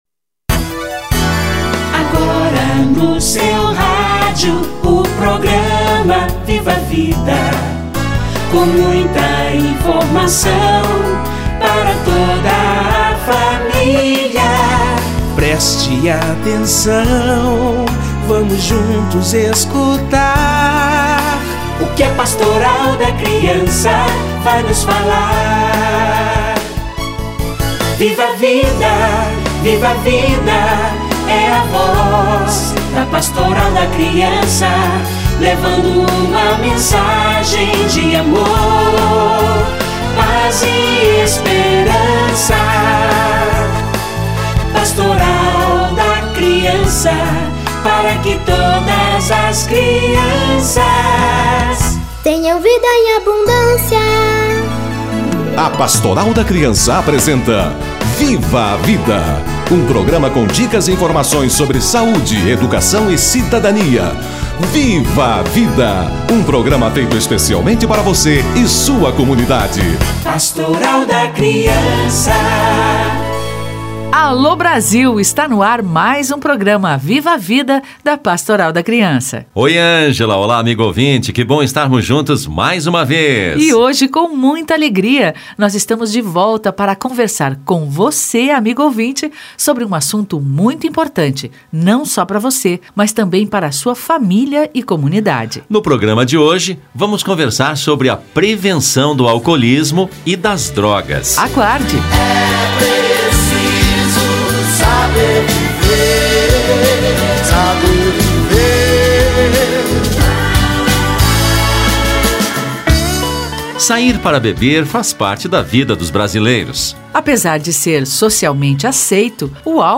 Prevenção ao uso de álcool e outras drogas - Entrevista